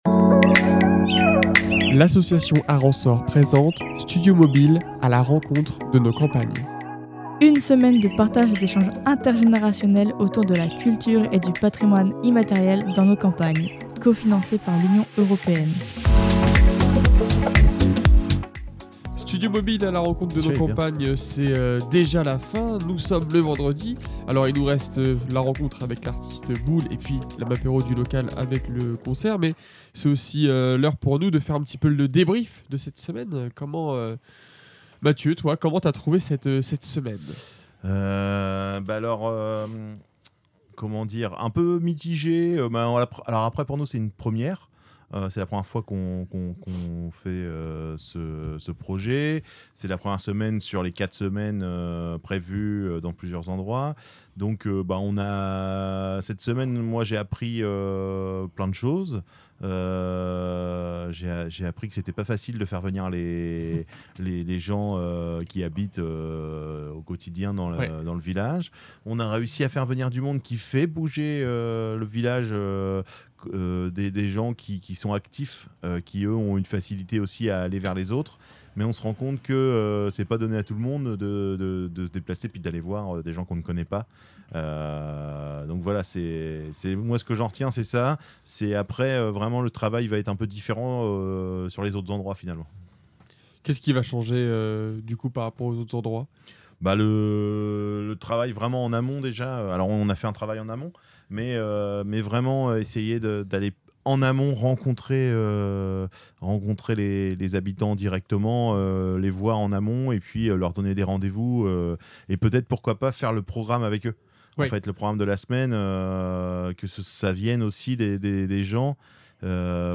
Entre échanges intergénérationnels, musique, découvertes et rencontres enrichissantes, on fait le bilan de cette belle semaine dans notre caravane 100 % solaire.